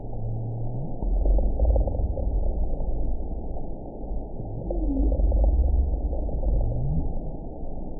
event 919625 date 01/14/24 time 11:21:05 GMT (1 year, 10 months ago) score 8.37 location TSS-AB08 detected by nrw target species NRW annotations +NRW Spectrogram: Frequency (kHz) vs. Time (s) audio not available .wav